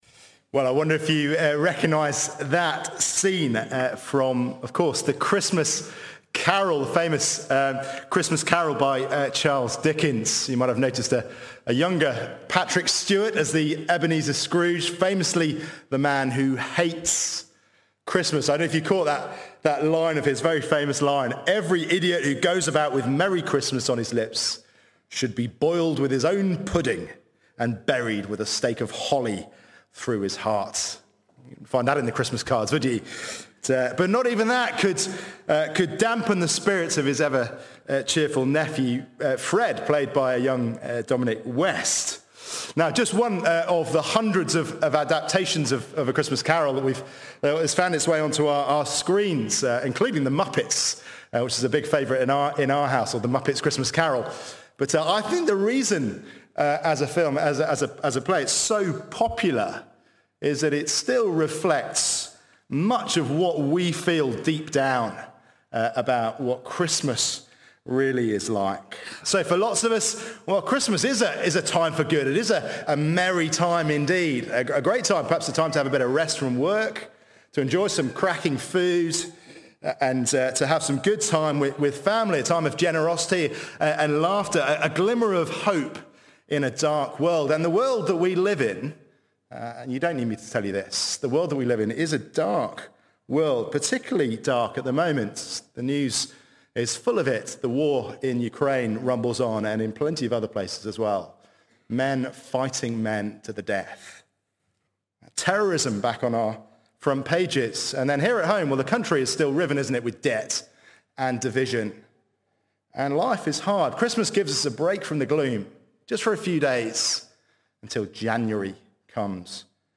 Sermons Archive - Page 3 of 187 - All Saints Preston